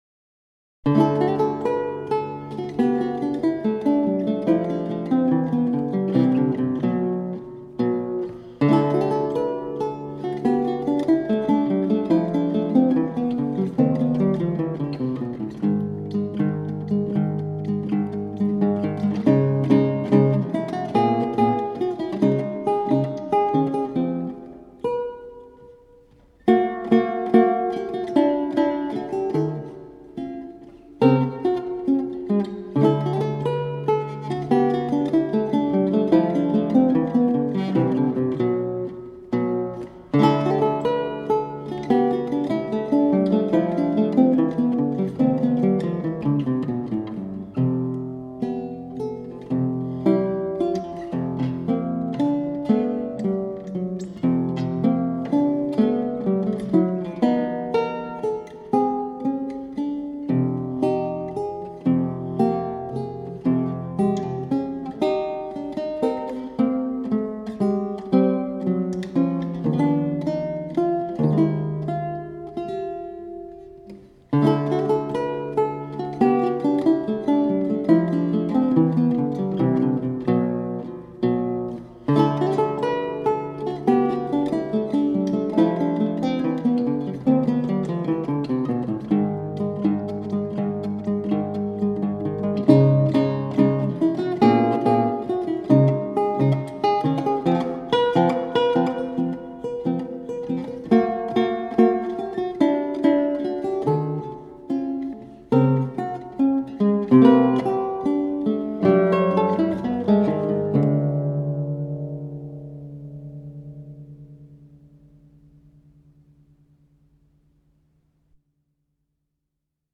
The musical language is light and free.